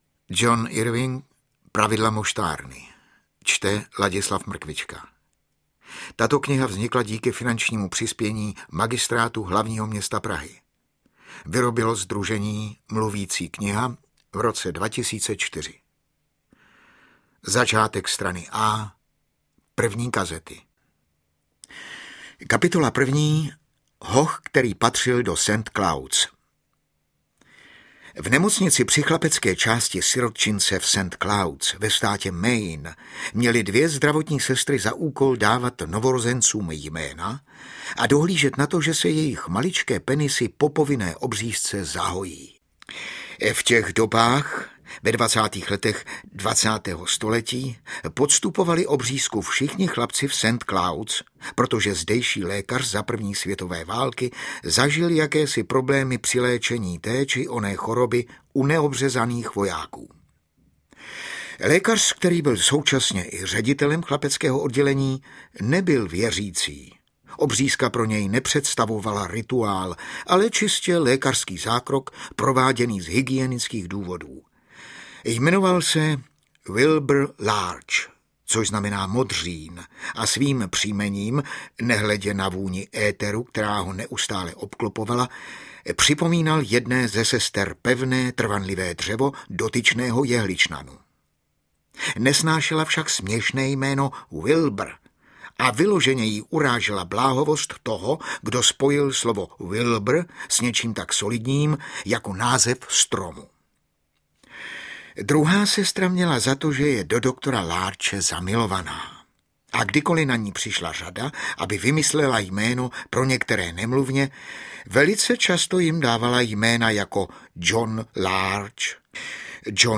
Čte: Ladislav Mrkvička